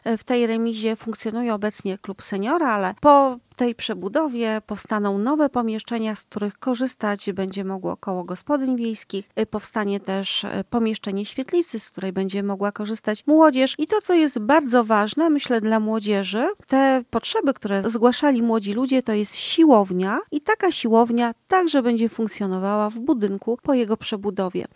Mówi burmistrz Zawichostu Katarzyna Kondziołka: